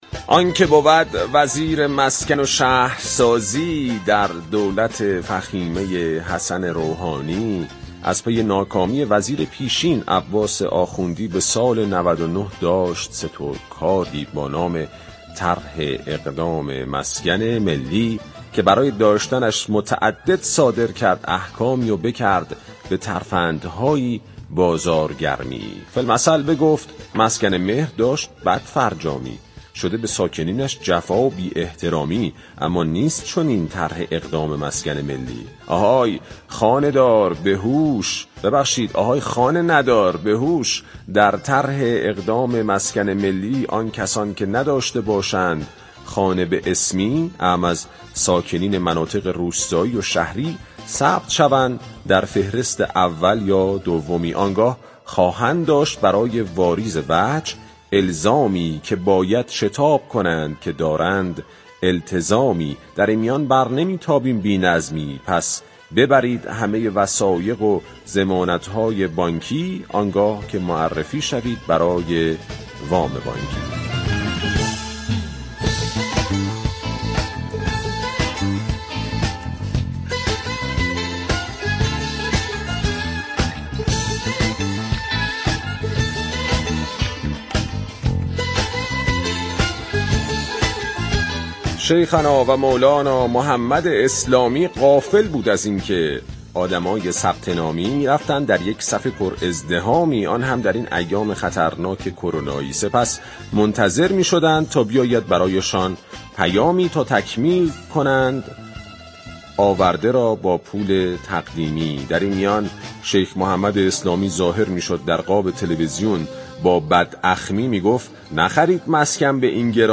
به گزارش پایگاه اطلاع رسانی رادیو تهران، محمد اسلامی وزیر راه، مسكن و شهرسازی در سومین روز از فروردین با نسخه نوروزی برنامه پارك شهر گفتگو كرد.